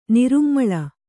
♪ nirummaḷa